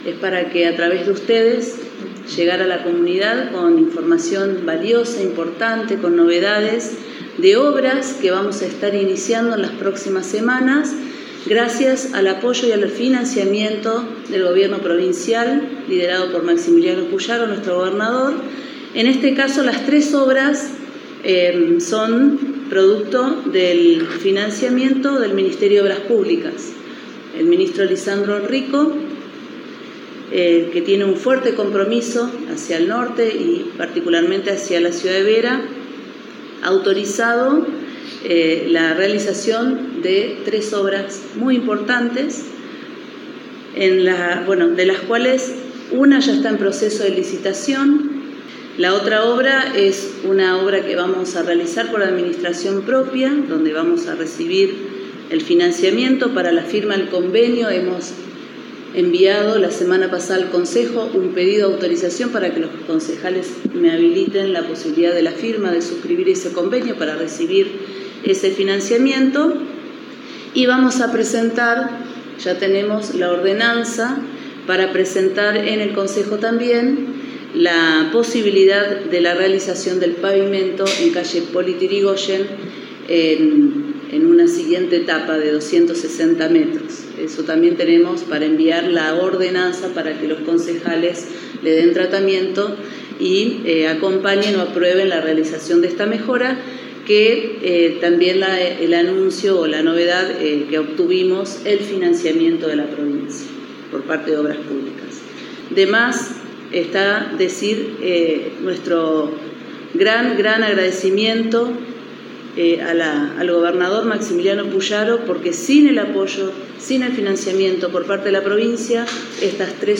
La intendente de Vera, Paula Mitre, presentó un plan de obras públicas que transformará la infraestructura de la ciudad y el distrito de Caraguatay.